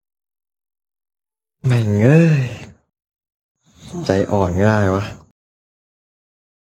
เสียงชาร์จแบตกวนๆ
เสียงแจ้งเตือนชาร์จแบตกวนๆ เสียงแมวตัวเมีย (female cat sound)
หมวดหมู่: เสียงเรียกเข้า